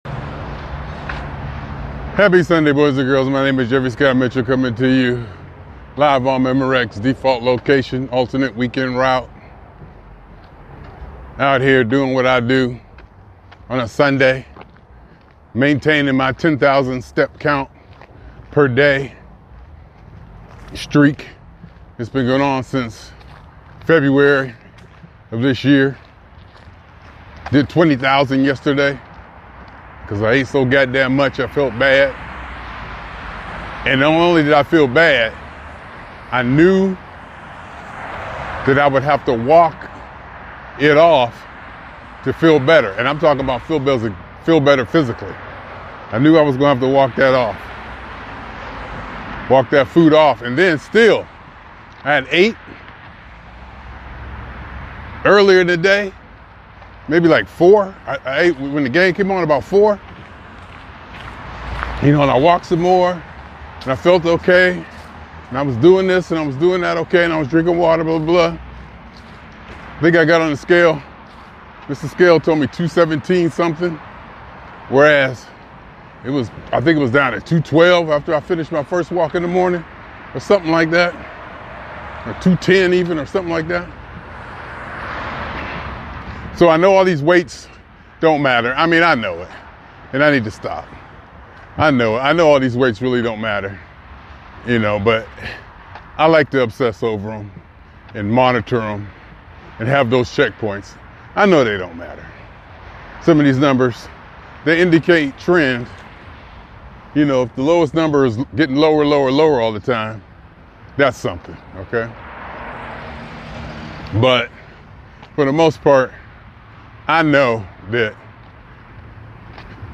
In this candid talk